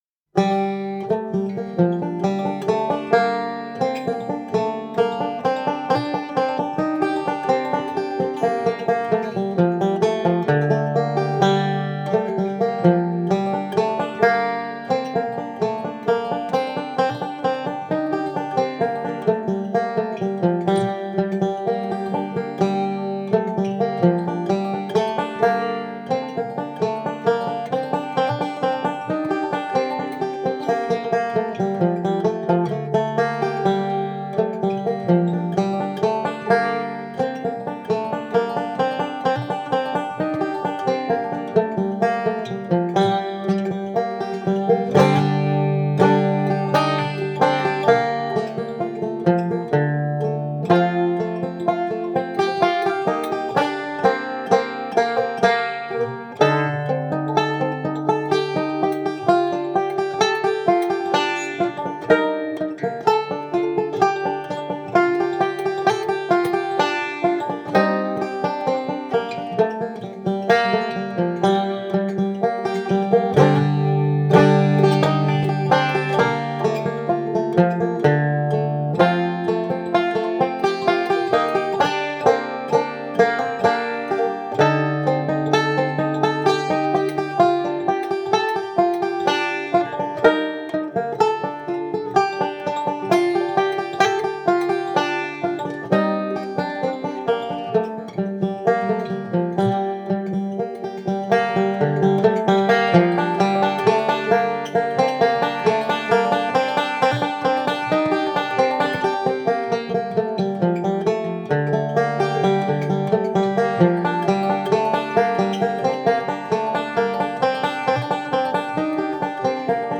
instrumental epic
Nordic folk music